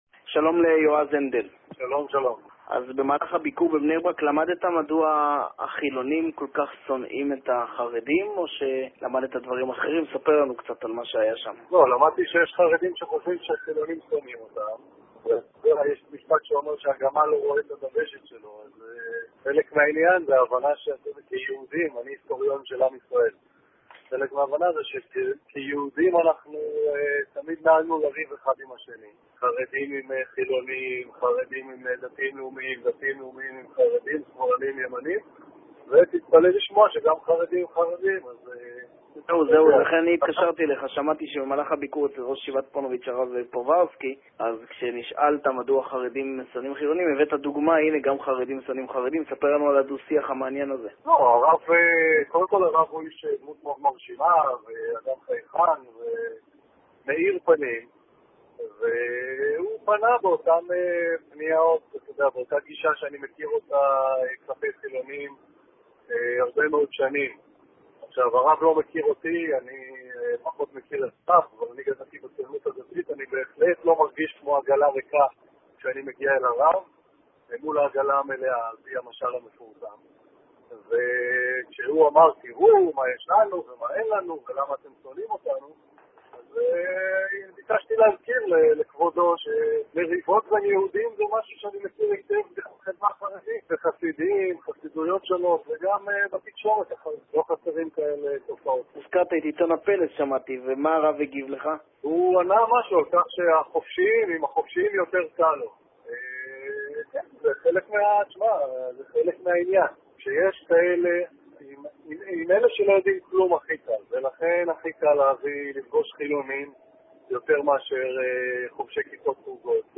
תימלול של הראיון שהתפרסם אמש באחד מקווי הנייעס: